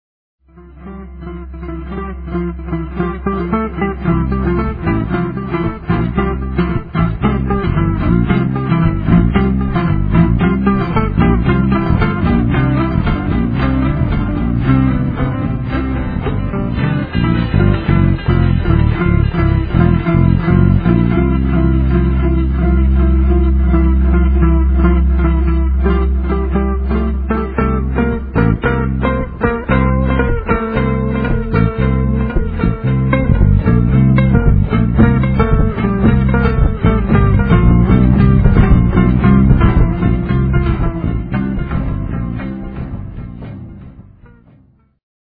Live improvization, Me (midi guitar, el. guitar)
bassguitar